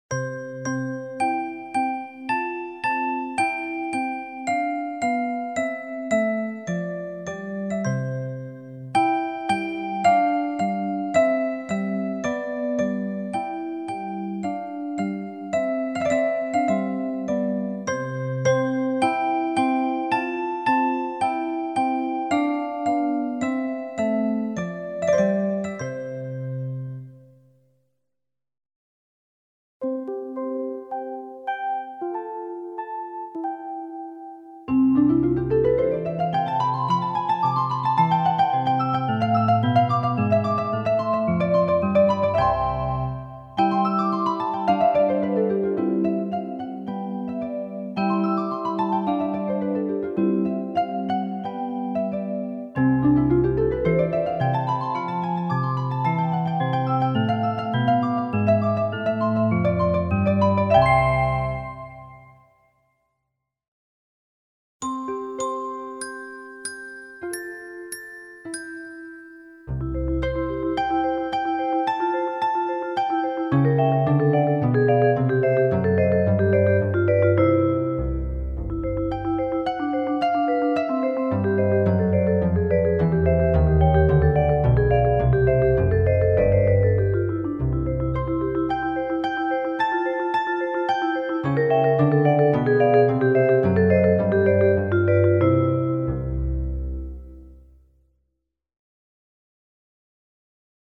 Классическая музыка величайшего композитора для взрослых и детей.